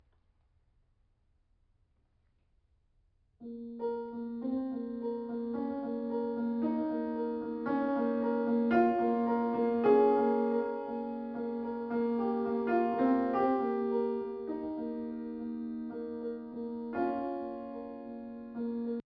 Weil ich den Sound aber recht schön finde, hab ich's probiert, mit dem S1 eigenem Bitcrusher nachzubauen. Das "Original" klingt aber noch irgendwie glockenspielartiger.